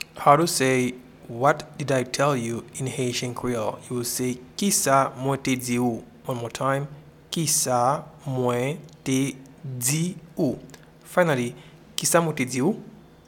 Pronunciation:
What-did-I-tell-you-in-Haitian-Creole-Kisa-mwen-te-di-ou.mp3